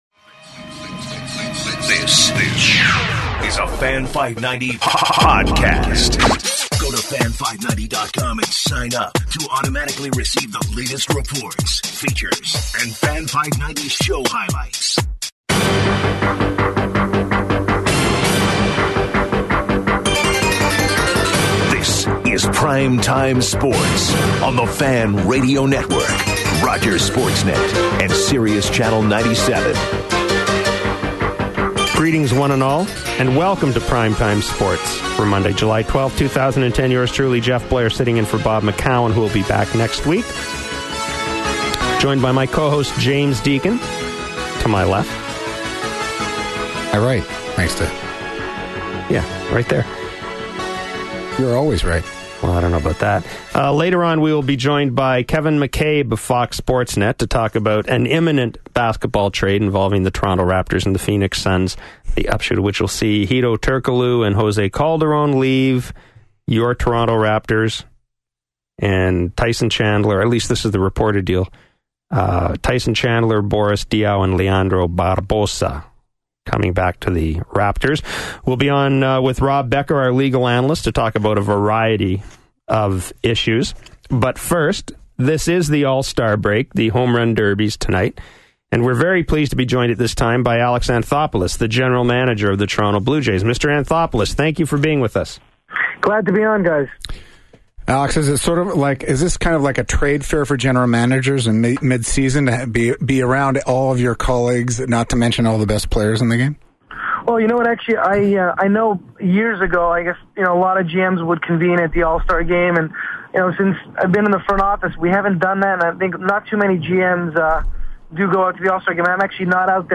Here is an interview from PTS with Jays GM, Alex Anthopoulos. It is a good interview and Alex talks about Buck, Bautista, Wallace, Romero, and Snider.